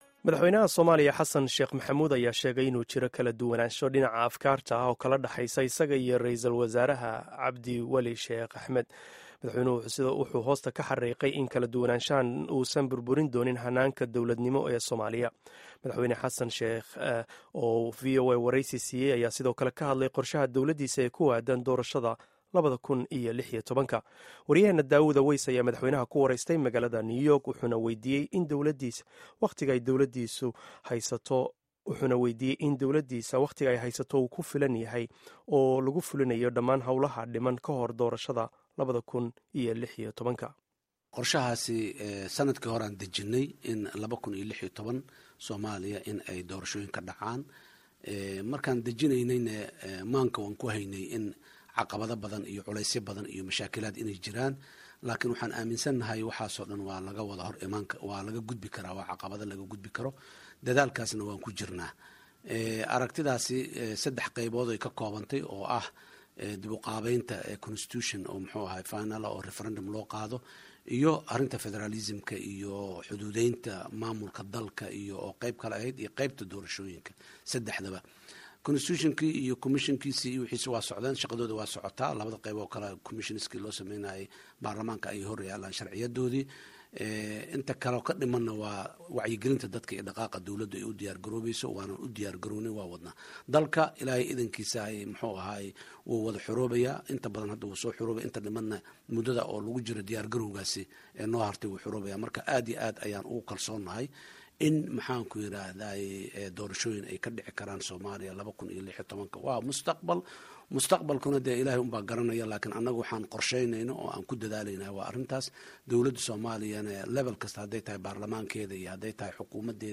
Dhageyso Wareysiga Madaxweyne Xassan Sheekh